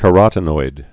(kə-rŏtn-oid)